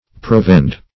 provend - definition of provend - synonyms, pronunciation, spelling from Free Dictionary Search Result for " provend" : The Collaborative International Dictionary of English v.0.48: Provend \Prov"end\, n. See Provand .
provend.mp3